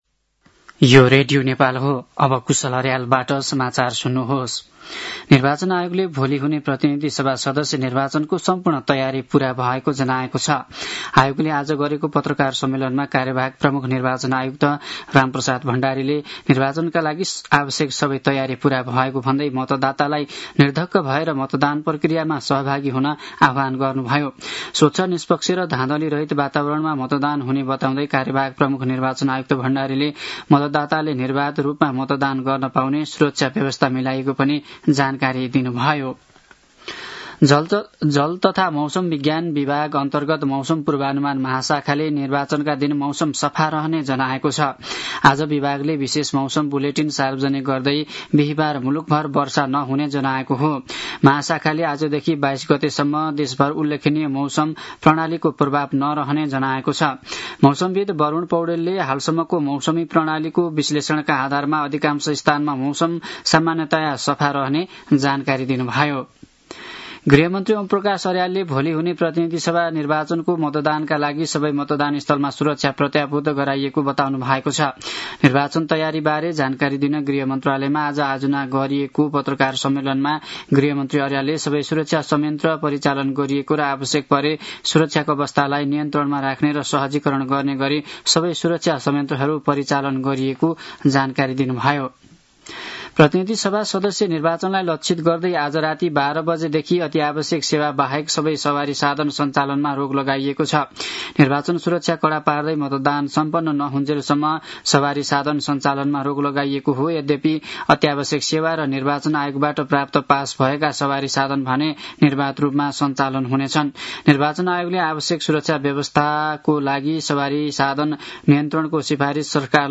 साँझ ५ बजेको नेपाली समाचार : २० फागुन , २०८२
5-pm-news-.mp3